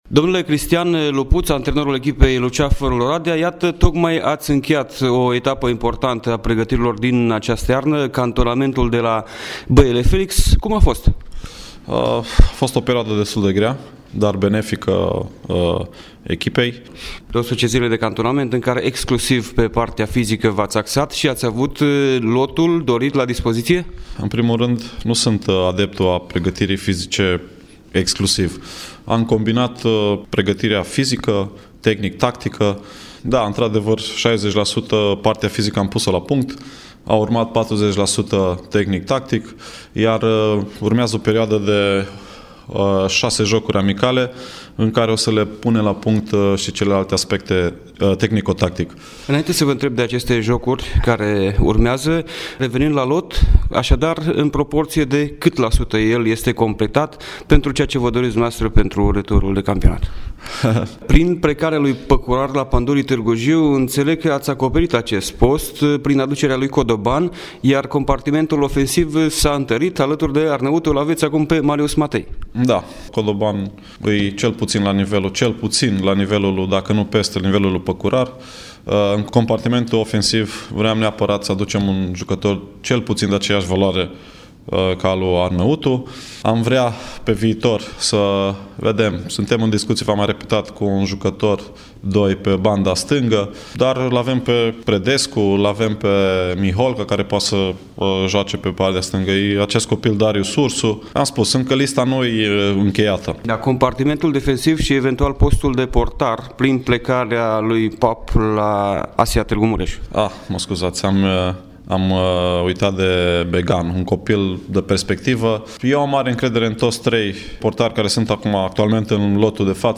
interviul complet